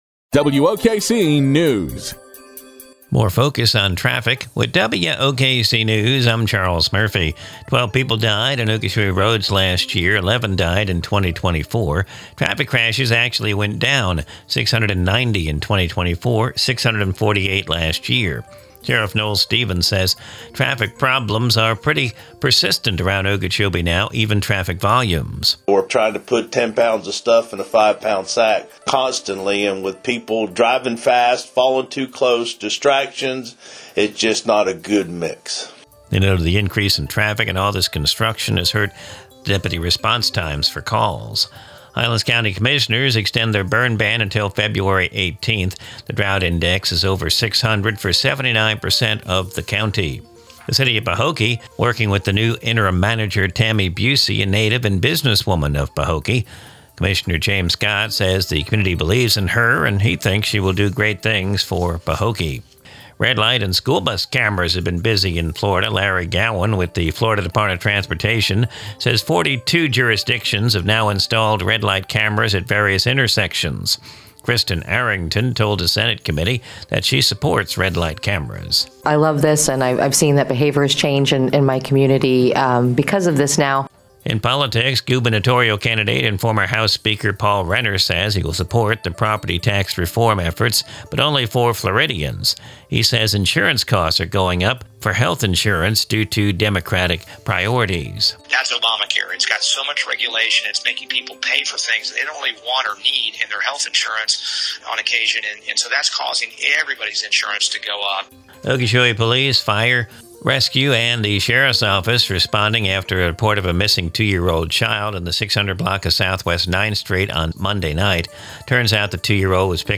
NEWS
Recorded from the WOKC daily newscast (Glades Media).